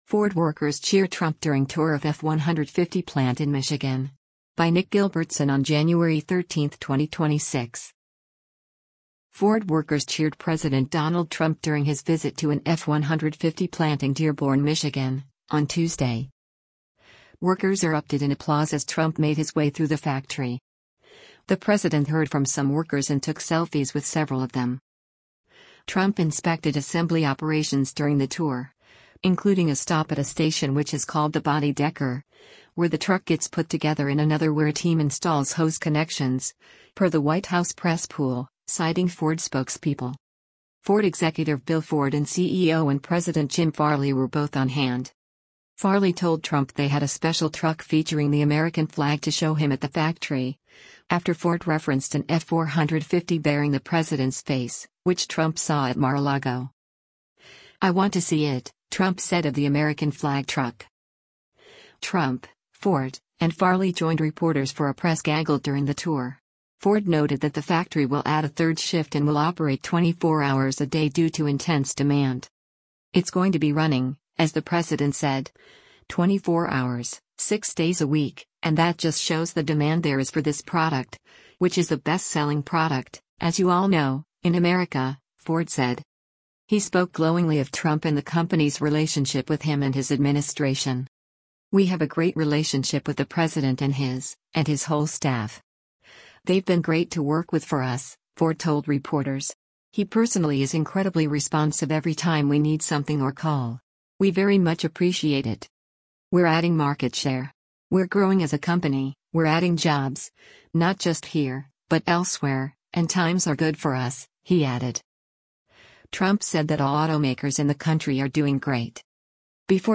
Ford workers cheered President Donald Trump during his visit to an F-150 plant in Dearborn, Michigan, on Tuesday.
Workers erupted in applause as Trump made his way through the factory.
Trump, Ford, and Farley joined reporters for a press gaggle during the tour.